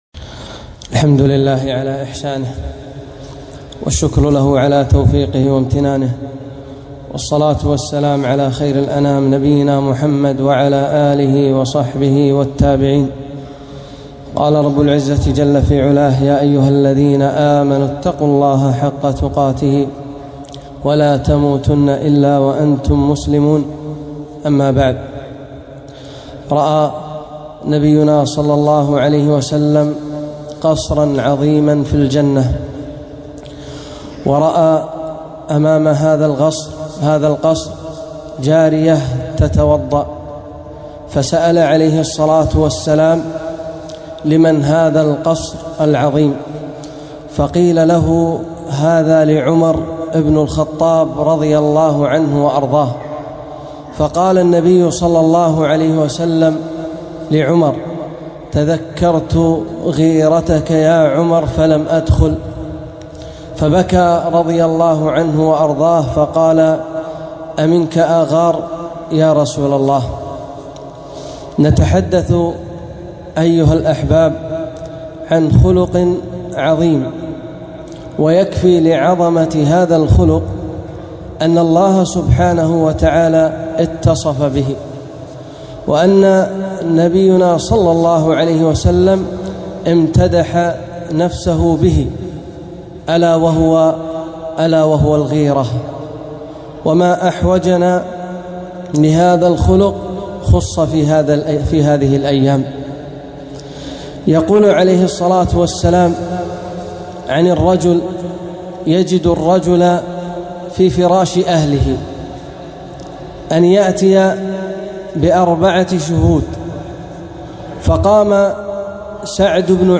خطبة - الغيرة